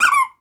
pgs/Assets/Audio/Comedy_Cartoon/squeaky_rubber_toy_cartoon_03.wav
squeaky_rubber_toy_cartoon_03.wav